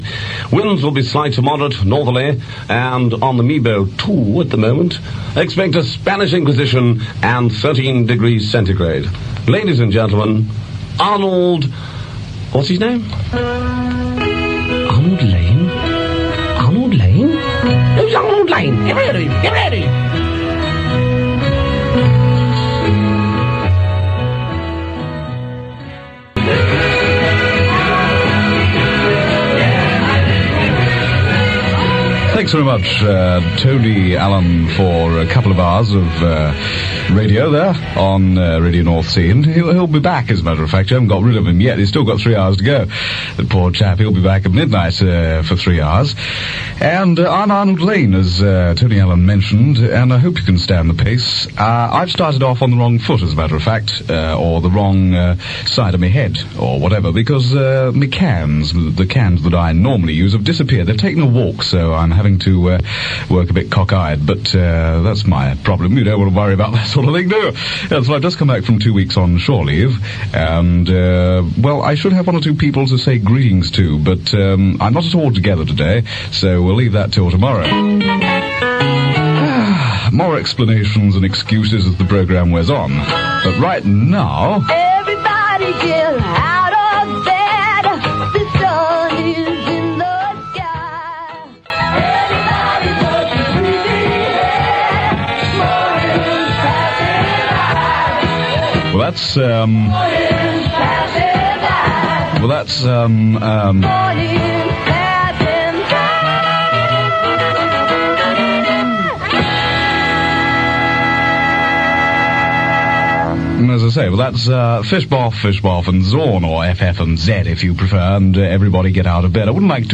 More recordings of RNI from FM.
reading the weather forecast